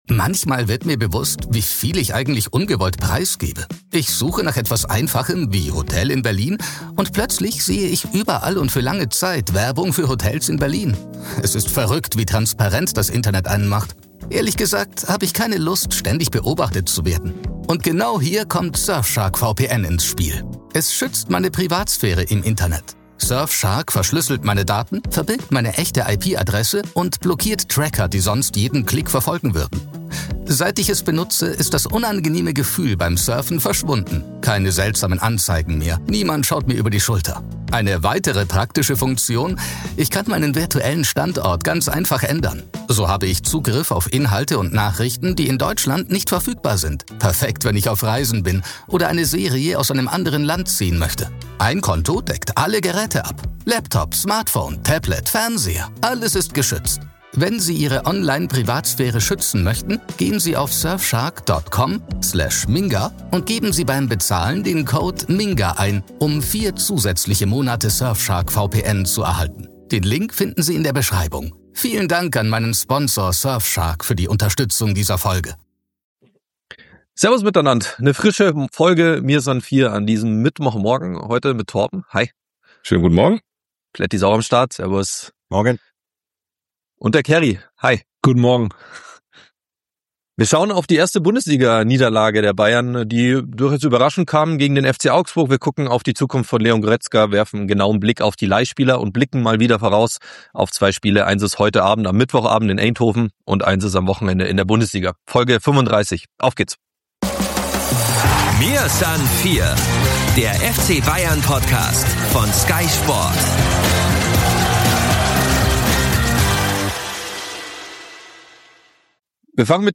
unsere vier Bayern-Reporter treffen aufeinander